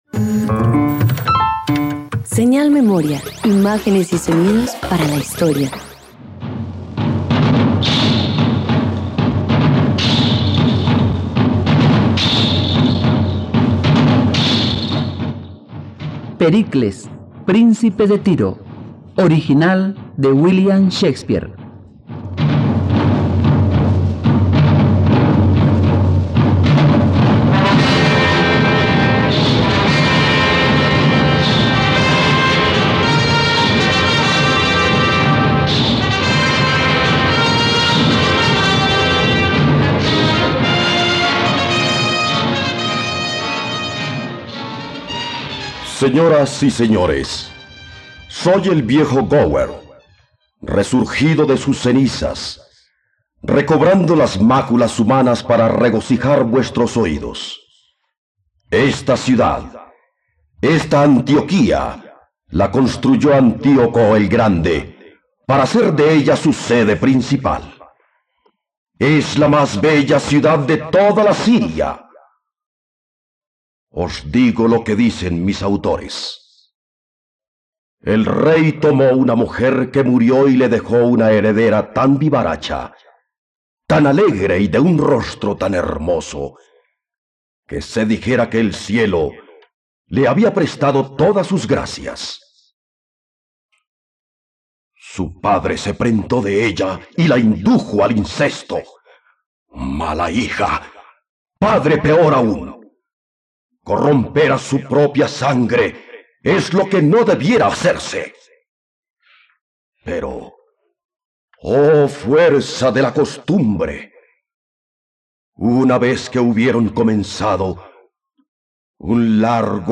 ..Radionovela. Escucha ahora la adaptación radiofónica de la obra de teatro Pericles, príncipe de Tiro en la plataforma de streaming de los colombianos: RTVCPlay.
La búsqueda de amor y redención lleva a Pericles a enfrentar desafíos mortales, naufragios y traiciones mientras lucha por reunir a su familia. Una adaptación radiofónica de la obra de teatro original del dramaturgo inglés William Shakespeare.